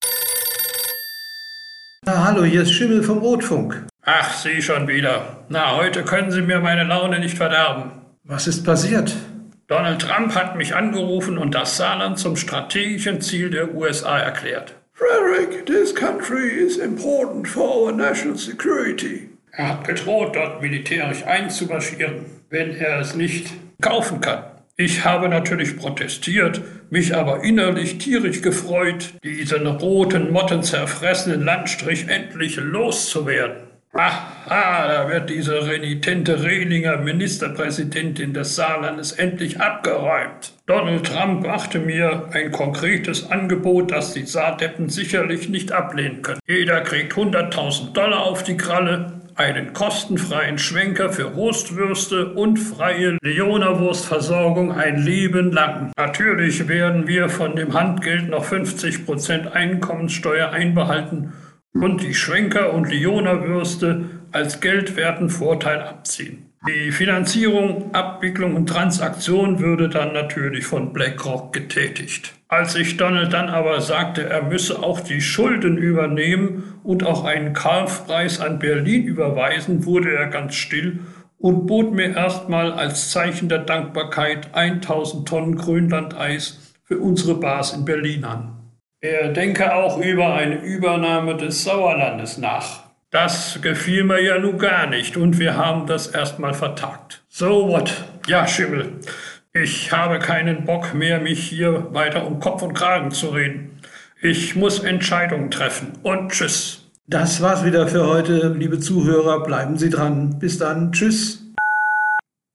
Merz Interview- Lösung des "Saarproblems" ?